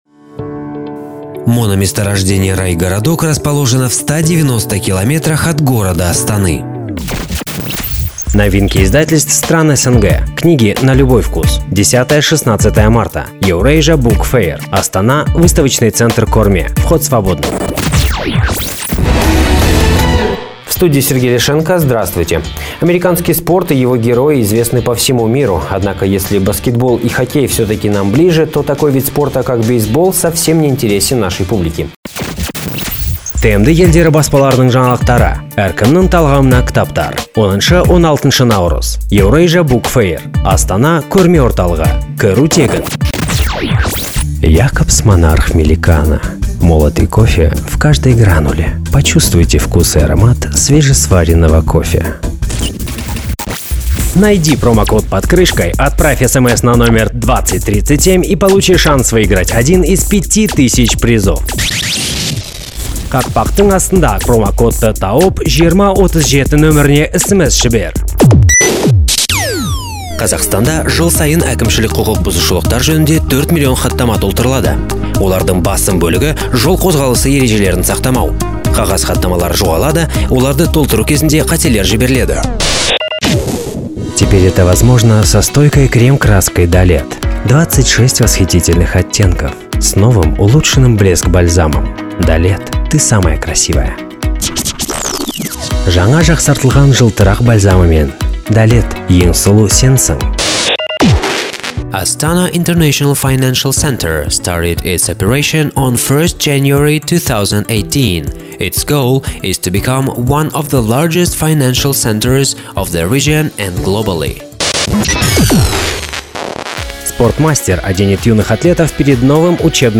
Пример звучания голоса
Муж, Другая
микрофон Antelope Audio Edge Solo внешняя звуковая карта M-Audio M-Track 2X2M